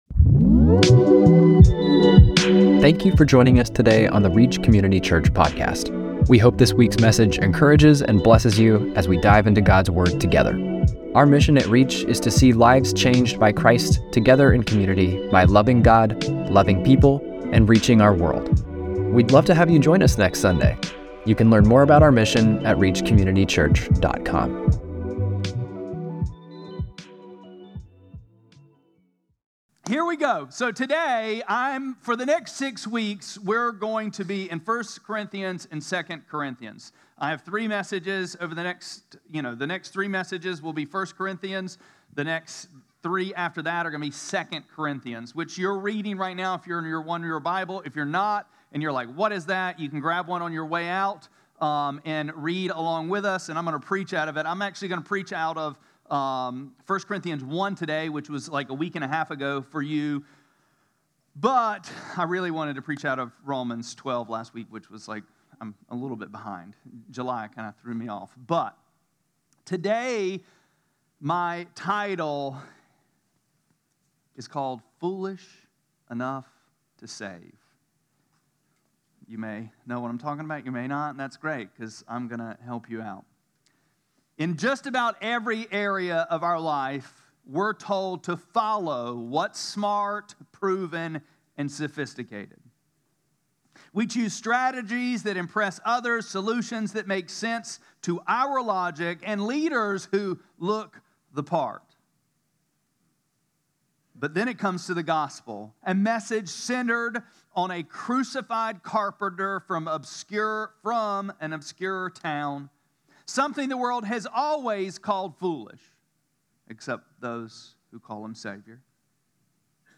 8-17-25-Sermon.mp3